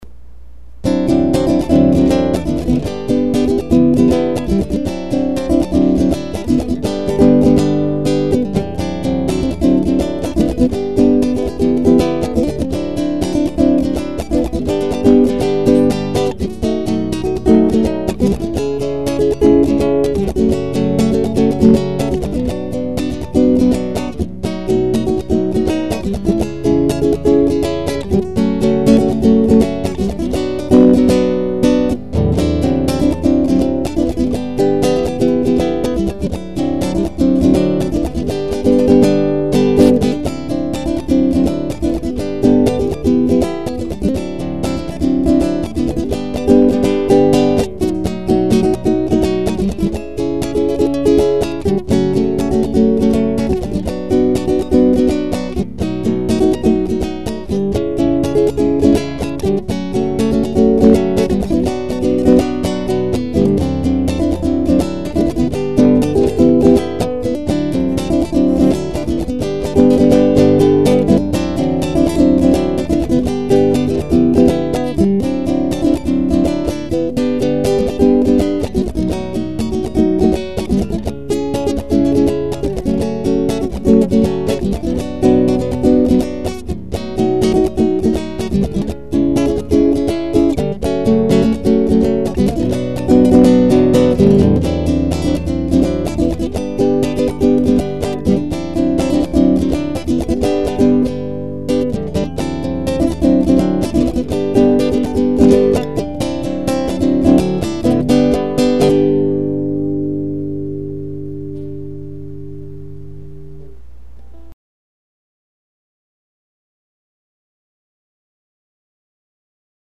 EstiloInstrumental